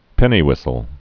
(pĕnē-wĭsəl, -hwĭs-)